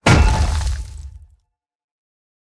Minecraft Version Minecraft Version latest Latest Release | Latest Snapshot latest / assets / minecraft / sounds / item / mace / smash_ground1.ogg Compare With Compare With Latest Release | Latest Snapshot
smash_ground1.ogg